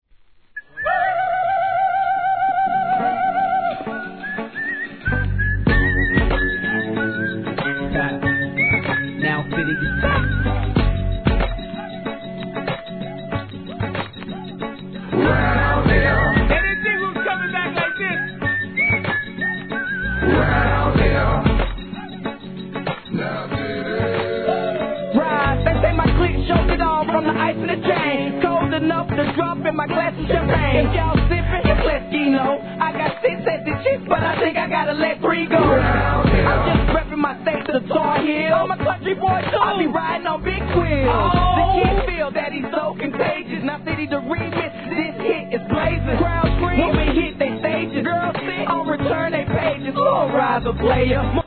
HIP HOP/R&B
カウボーイ風なイントロの口笛に銃声が効果的!